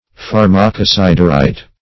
Search Result for " pharmacosiderite" : The Collaborative International Dictionary of English v.0.48: Pharmacosiderite \Phar`ma*co*sid"er*ite\, n. [Gr. fa`rmakon drug, poison + E. siderite.]